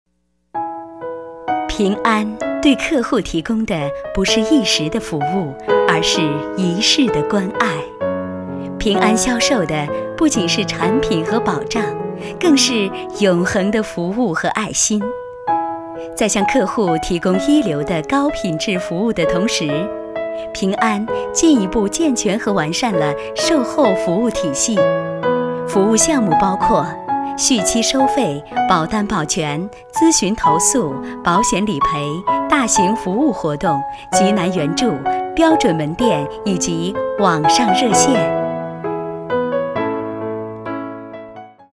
配音风格： 年轻 时尚